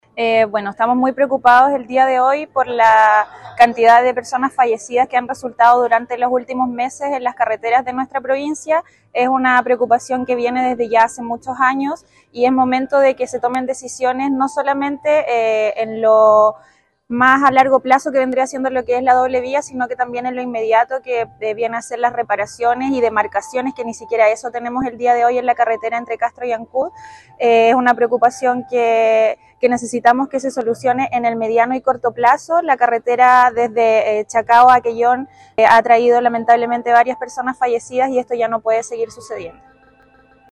Una conferencia de prensa, ofrecieron en Puerto Montt, los consejeros regionales de la Provincia de Chiloé, para presentar sus inquietudes y su posición, con respecto al proyecto de la doble vía, que, en su primera fase, considera el tramo entre Chacao y Chonchi.